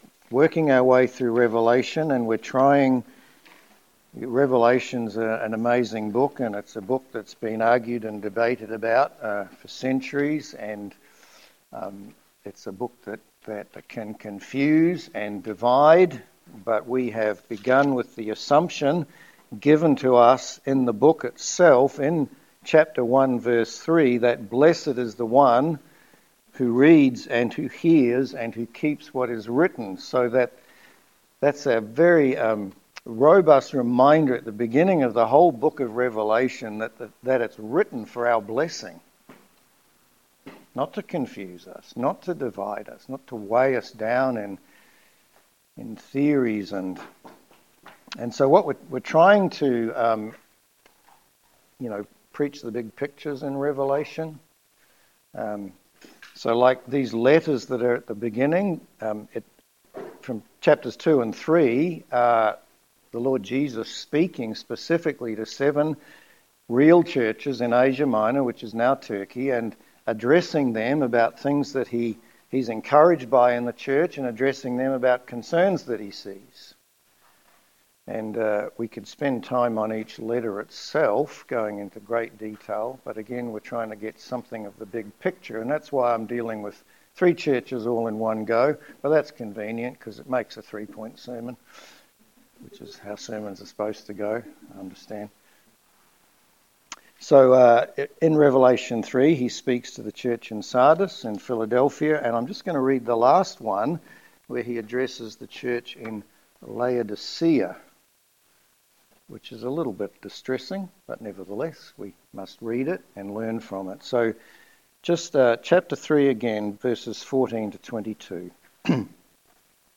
Revelation 3:1-22 Service Type: Sermon Are we as a church hot